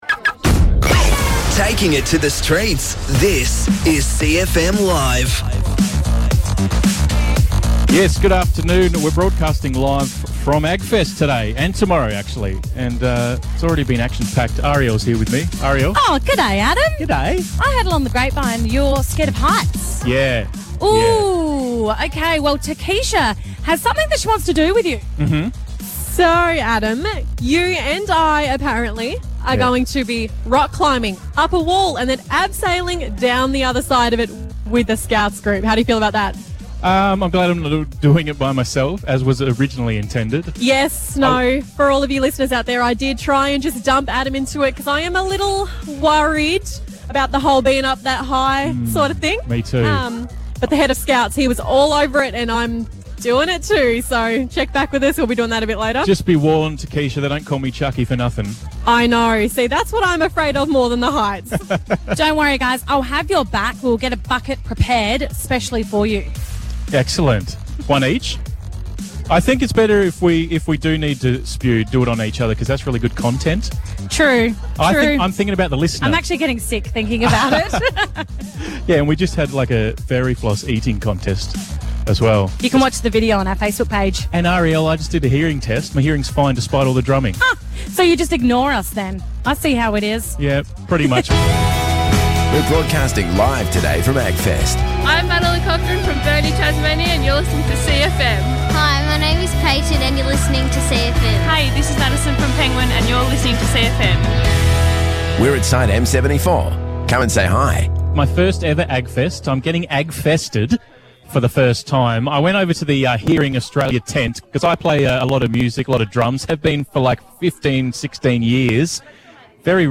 The full Long Lunch broadcast from Agfest 6/5/2021.